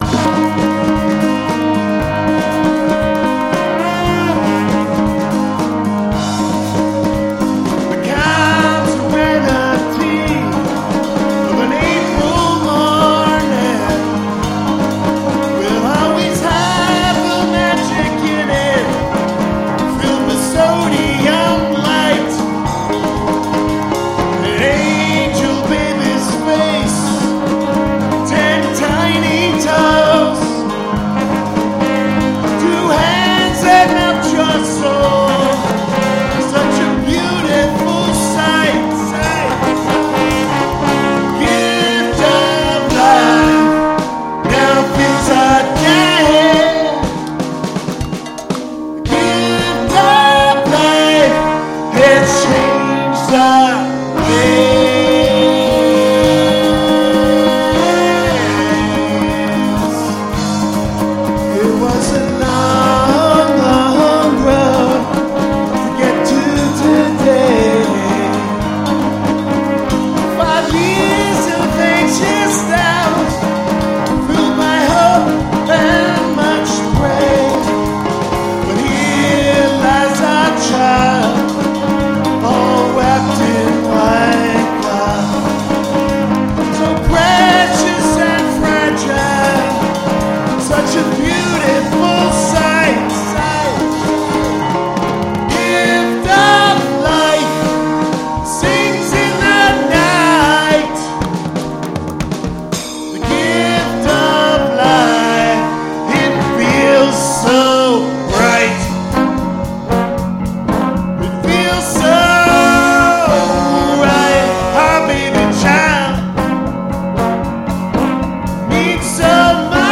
Self Published Studio Album